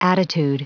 Prononciation du mot attitude en anglais (fichier audio)
Prononciation du mot : attitude